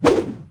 FootSwing3.wav